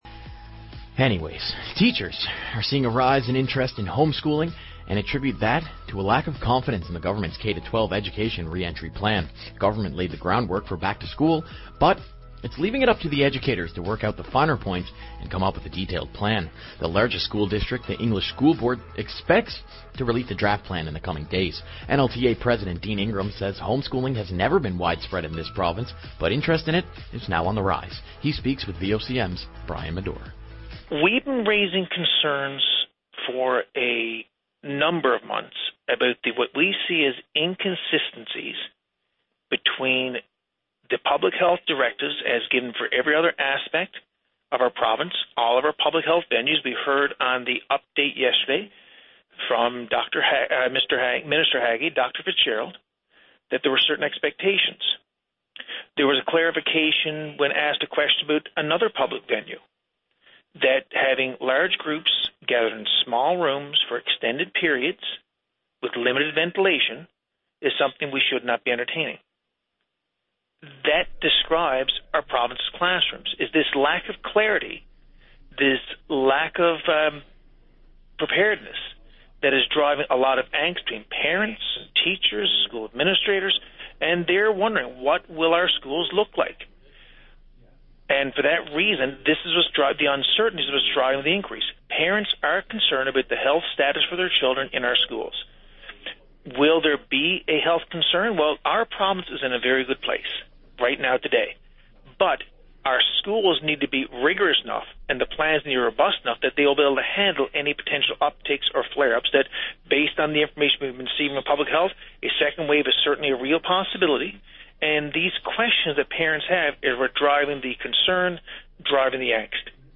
Media Interview - VOCM Morning Show Aug 14, 2020